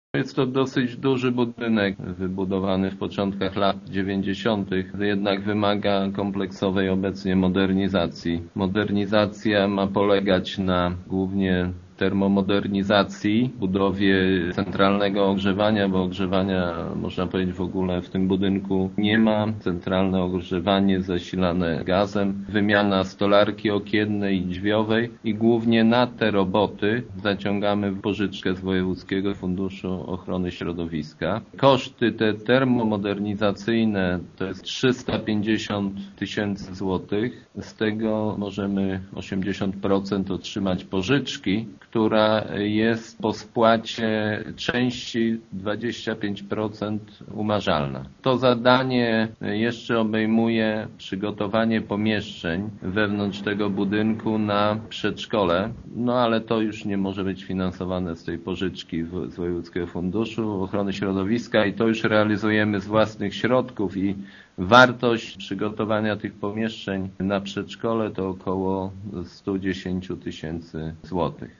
„Prace będą polegać przede wszystkim na termomodernizacji obiektu oraz przebudowaniu wnętrza” – informuje wójt Jacek Anasiewicz: